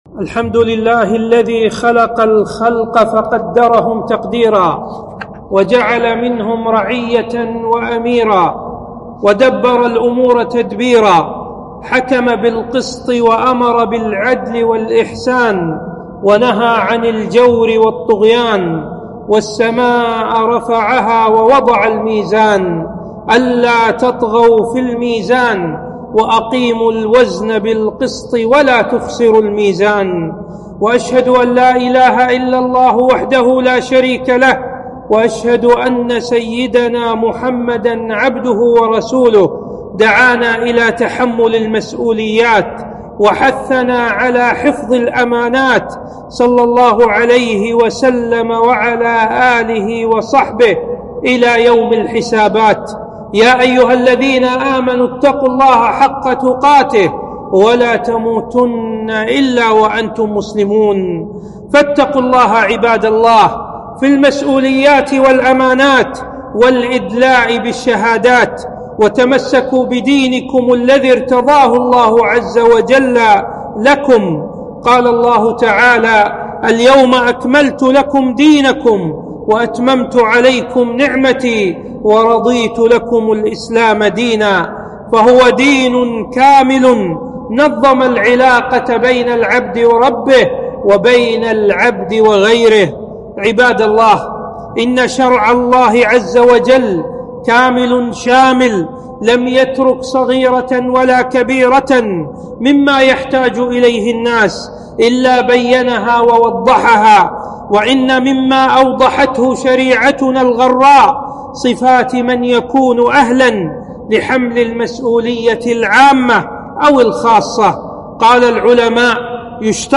خطبة - منكرات ومخالفات في الانتخابات